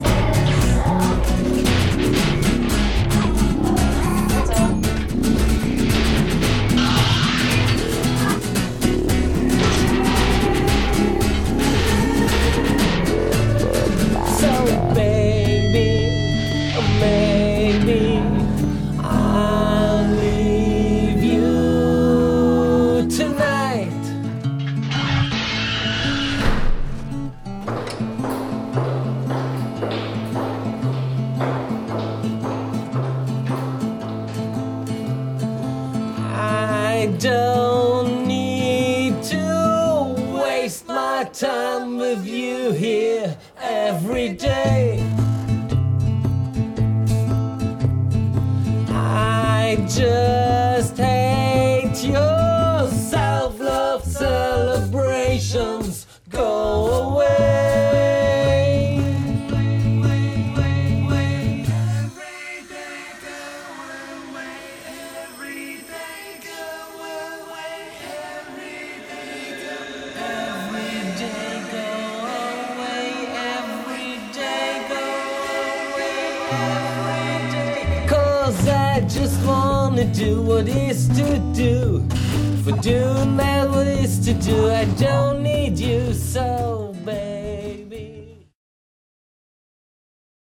vocal bass line, electronics
click-clacks